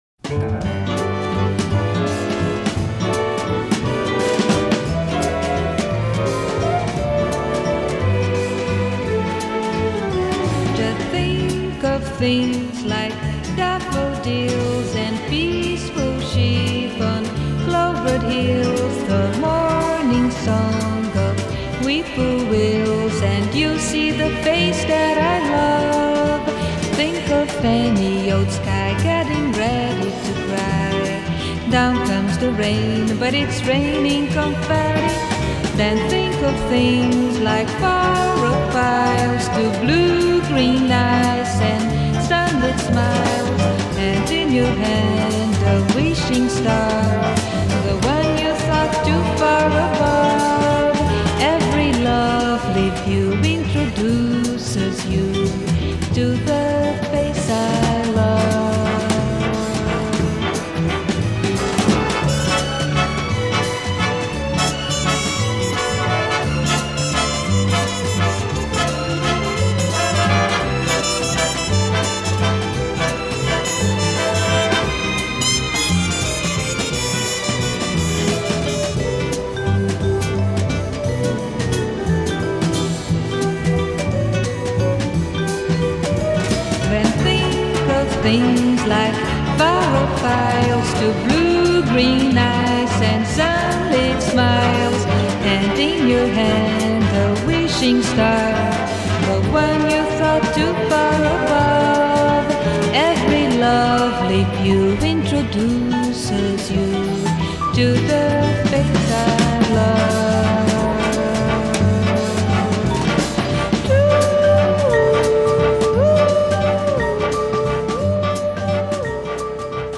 Жанр: Vocal, Jazz